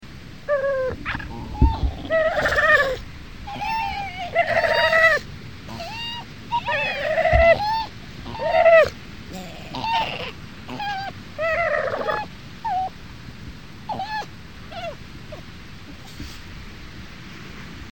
Colonie d'otaries de Kerguelen. Ile de Croÿ.